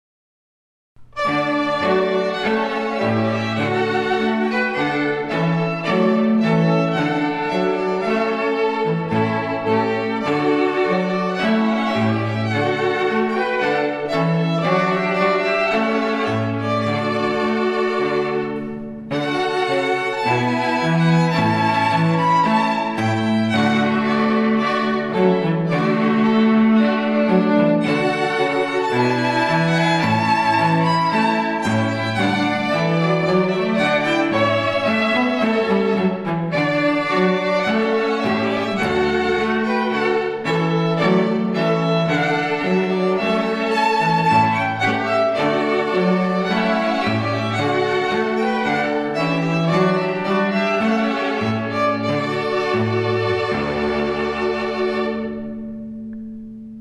Trumpet Voluntary Clarke String Quartet